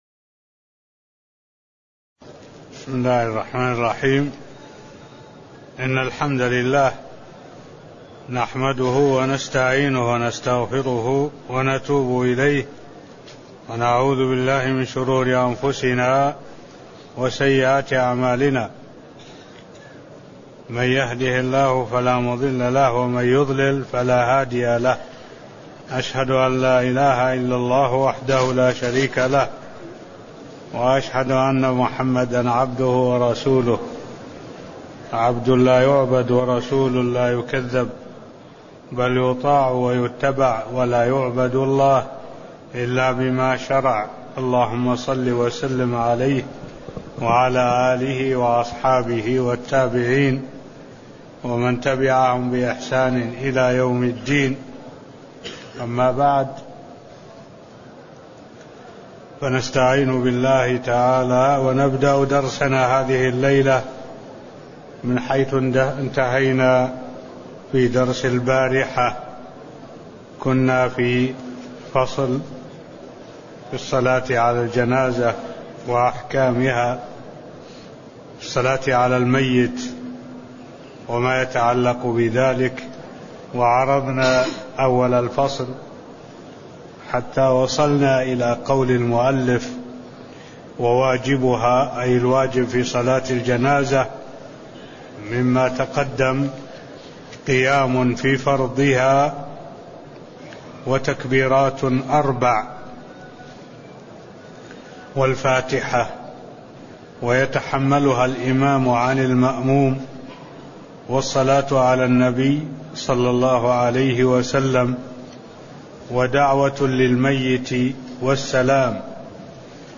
تاريخ النشر ١٦ ذو الحجة ١٤٢٦ هـ المكان: المسجد النبوي الشيخ: معالي الشيخ الدكتور صالح بن عبد الله العبود معالي الشيخ الدكتور صالح بن عبد الله العبود واجبات صلاة الجنائز (002)